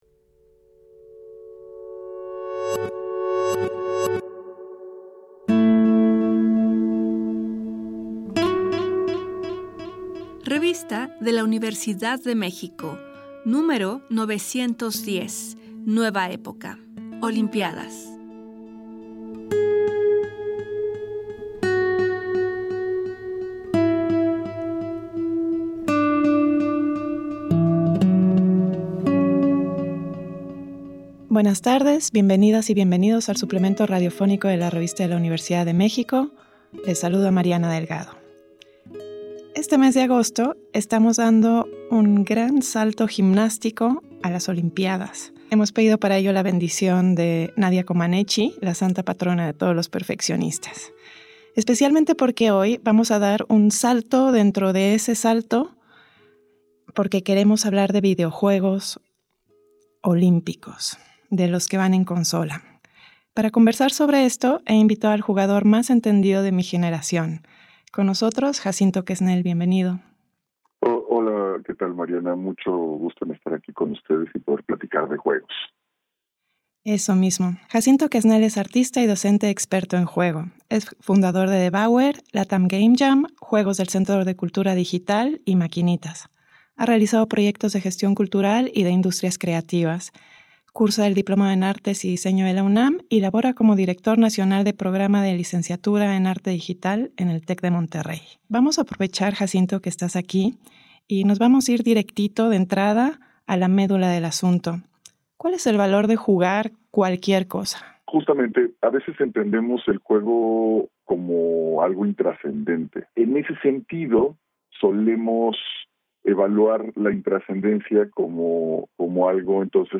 Fue transmitido el jueves 8 de agosto de 2024 por el 96.1 FM.